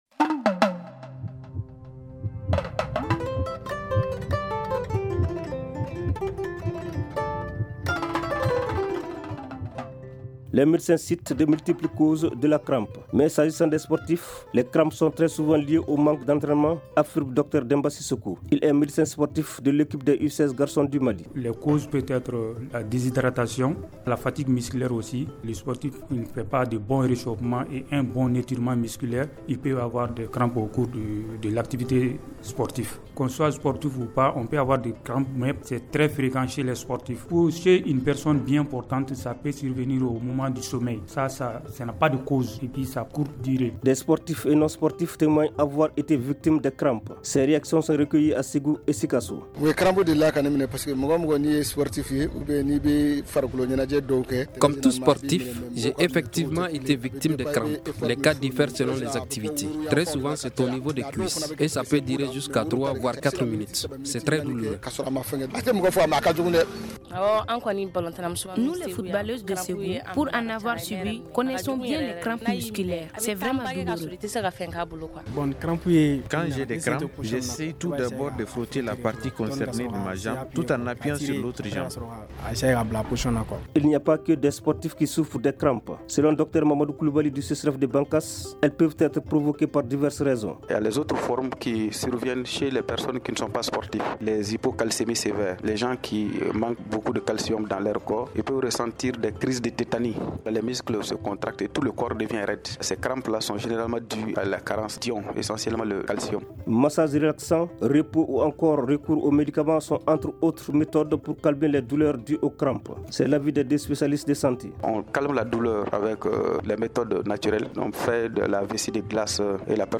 Des spécialistes indiquent ces cas sont souvent dus aux crampes. Lorsque elles se déclenchent la douleur, est importante témoignent des sportifs rencontrés à Bamako, Ségou ou ailleurs au Mali.
Les médecins évoquent d’autres raisons dans ce magazine santé consacré aux crampes.